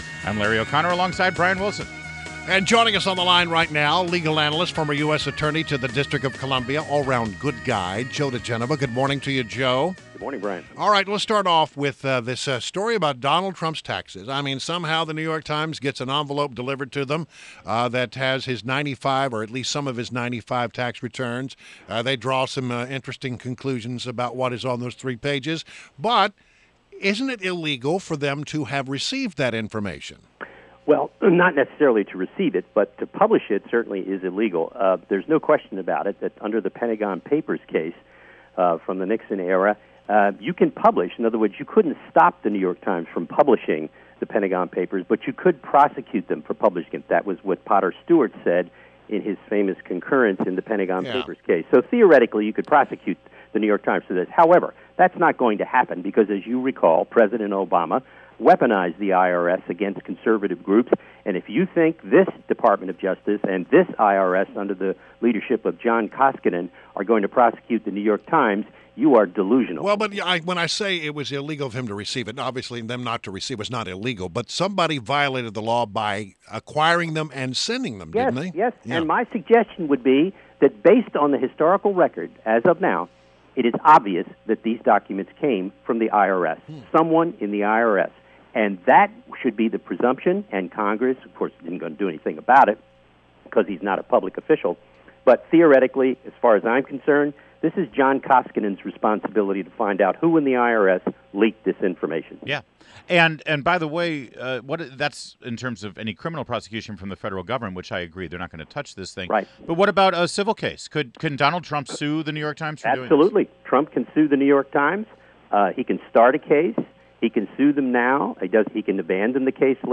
WMAL Interview - JOE DIGENOVA - 10.03.16
INTERVIEW — JOE DIGENOVA — Legal Analyst and former U.S. Attorney to the District of Columbia